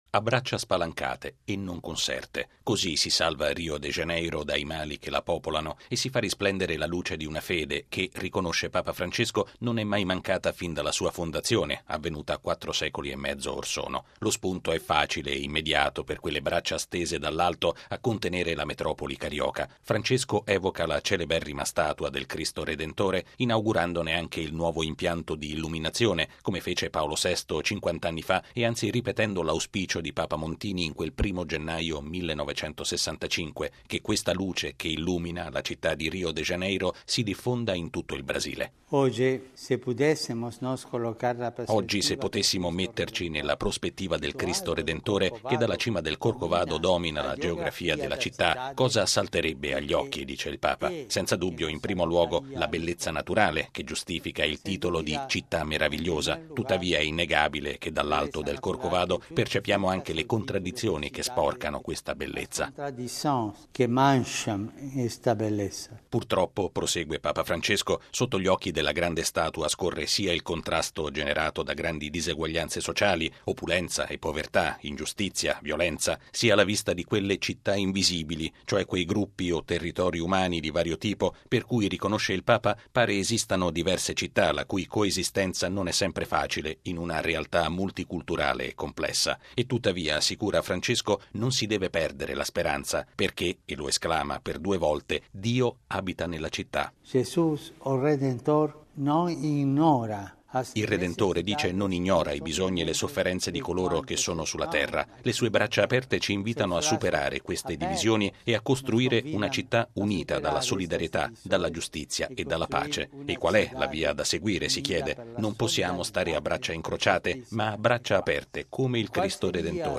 La luce che irradia dal Cristo del Corcovado illumini il cuore di tutti i brasiliani. È l’augurio che Papa Francesco rivolge in un videomessaggio agli abitanti di Rio de Janeiro, in occasione dei 450 anni di fondazione della città carioca.
Il servizio